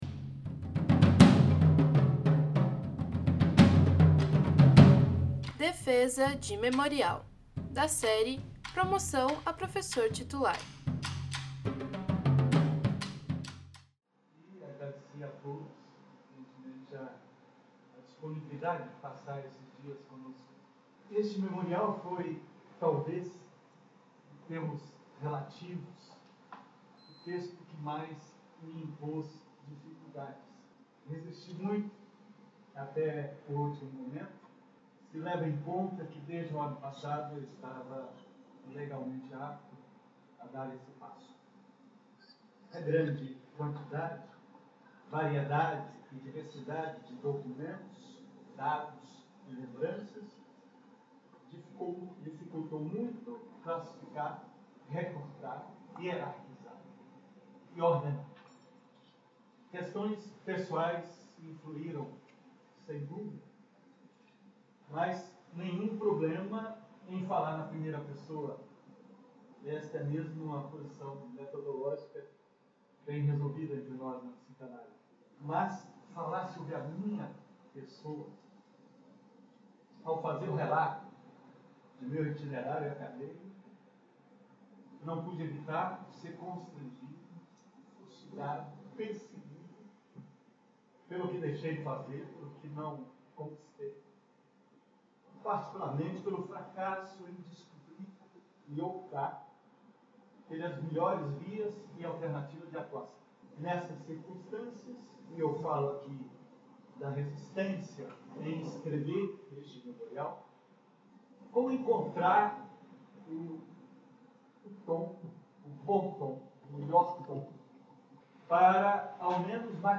no Auditório do MArquE